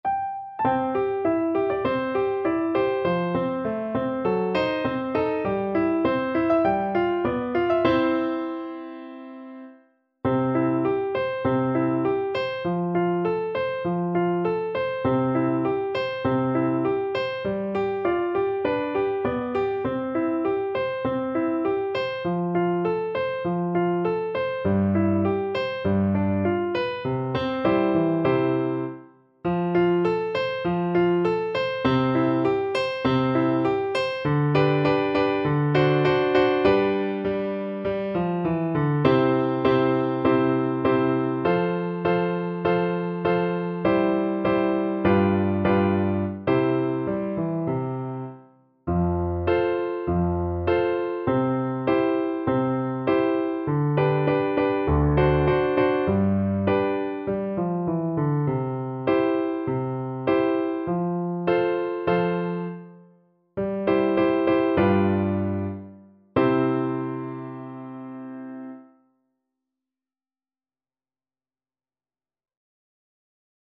Flute
4/4 (View more 4/4 Music)
C major (Sounding Pitch) (View more C major Music for Flute )
~ = 100 Moderato
Traditional (View more Traditional Flute Music)